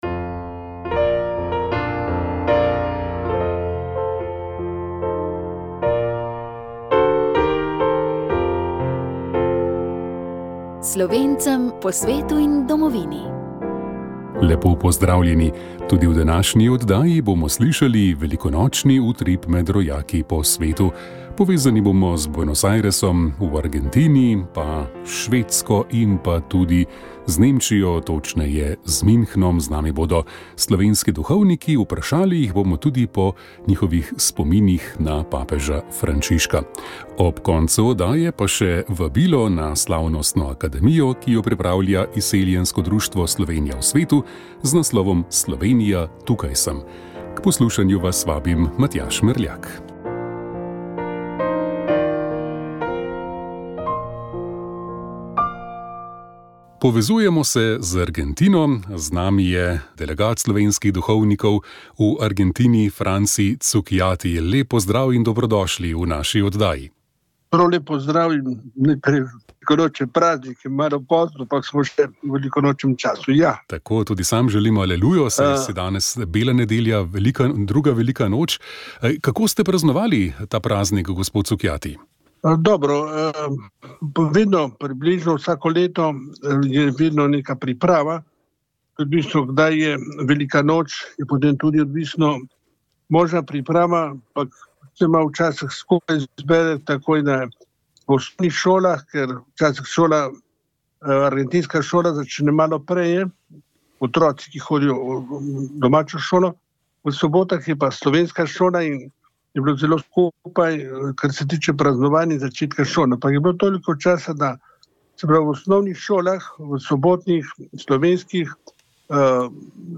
Molitev je vodil salezjanec